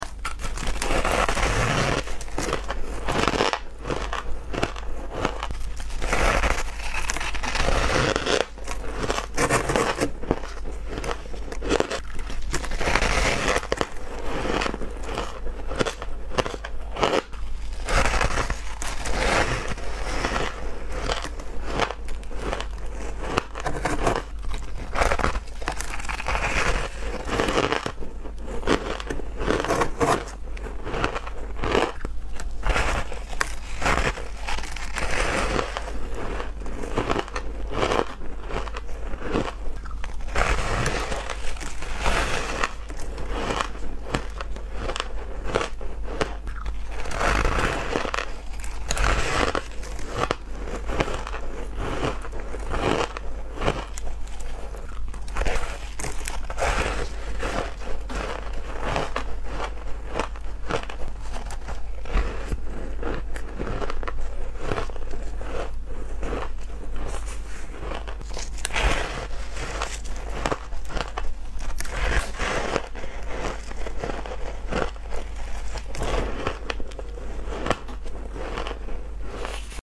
Freeze Froet Chunk Asmr 🍧 Sound Effects Free Download